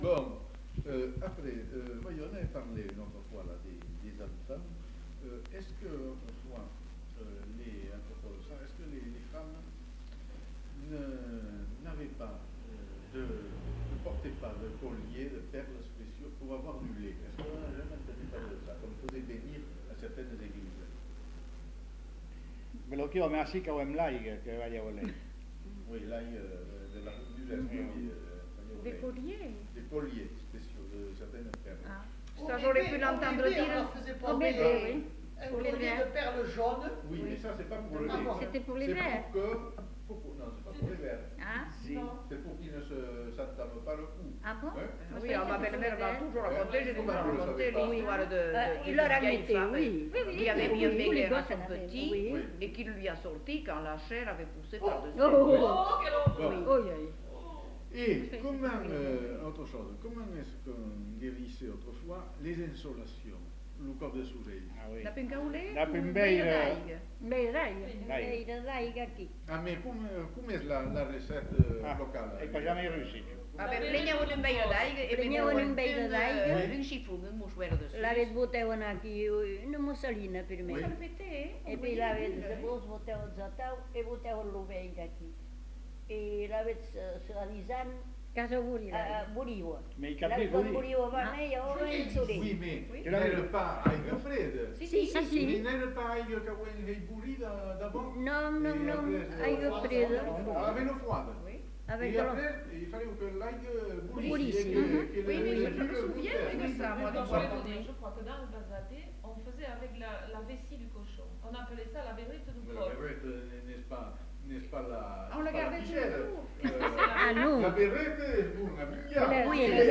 Lieu : Uzeste
Genre : témoignage thématique